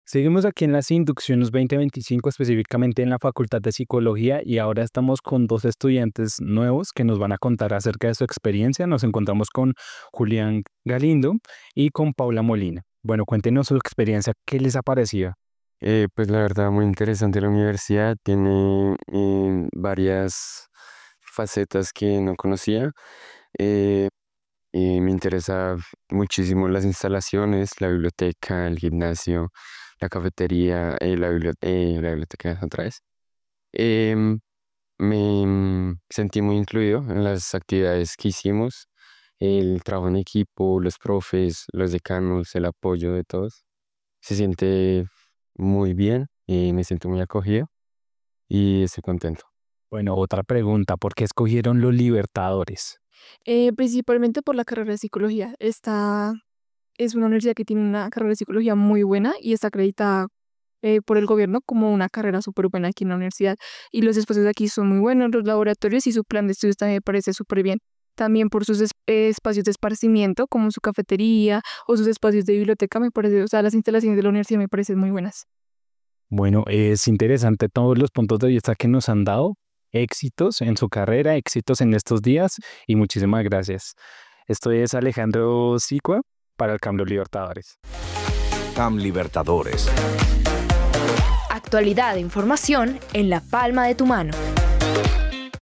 En la siguiente entrevista conozca la experiencia de dos alumnos que inician su carrera universitaria.